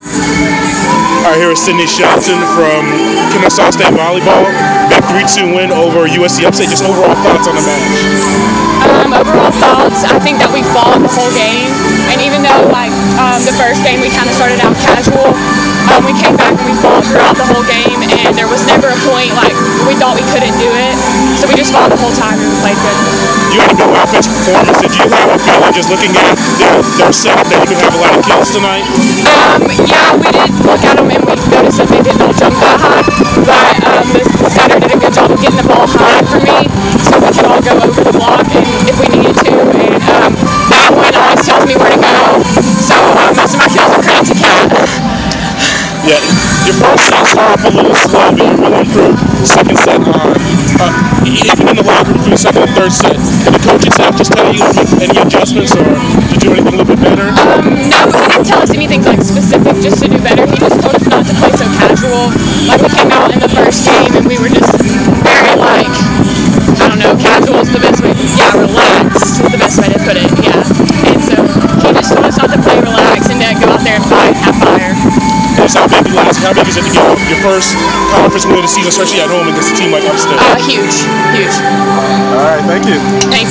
Inside the Inquirer: Postmatch interview with